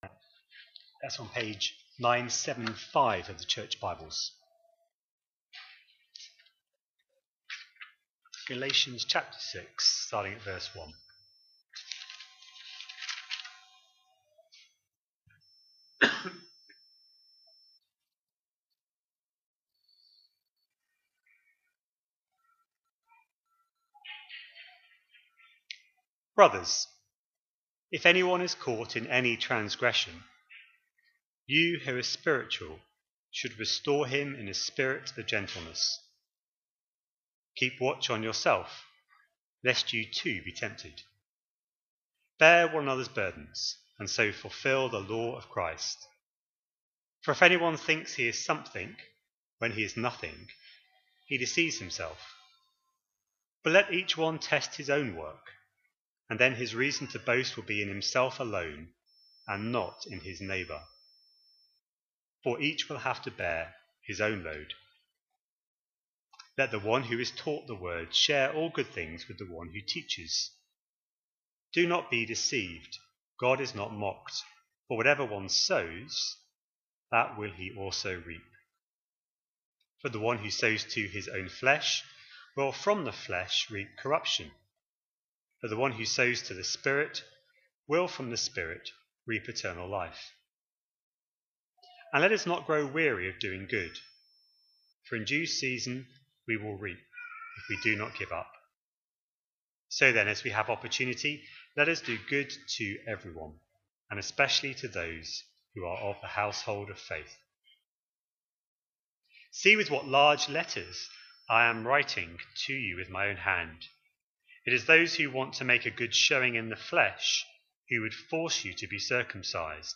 A sermon preached on 6th July, 2025, as part of our Galatians series.